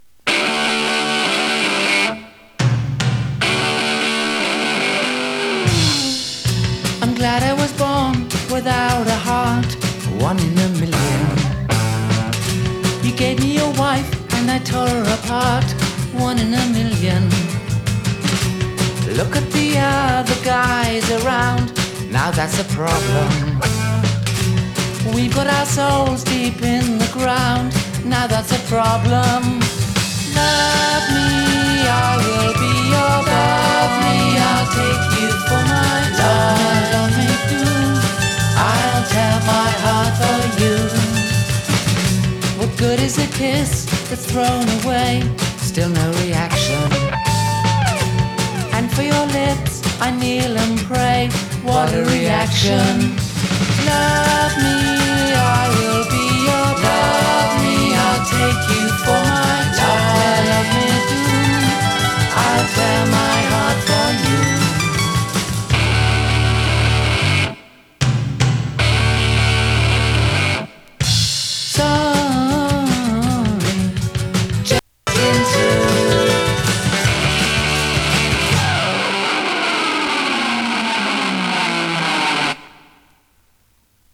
ネオアコ
インディーポップ